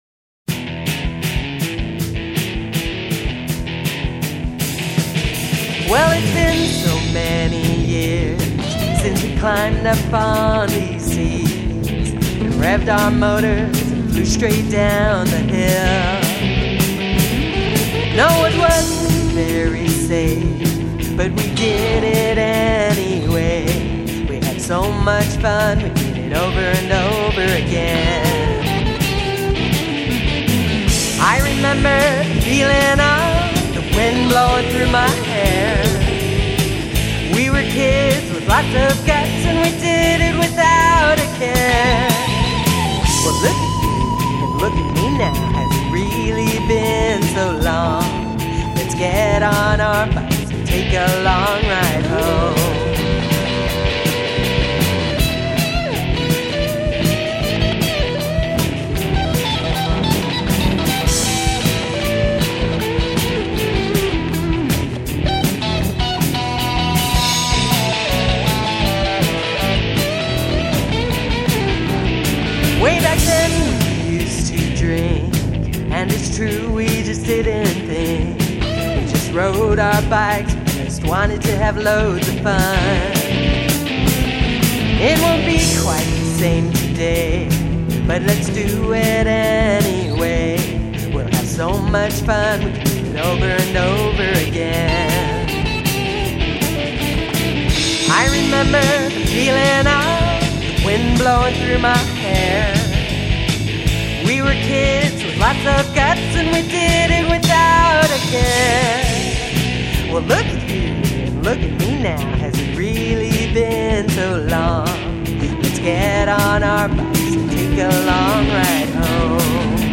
Vocals, Rhythm Guitar
Electric Guitar
Bass Guitar
Drums
Blues Rock in E